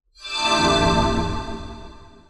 Trimmed-Healing-Spells
sfx updates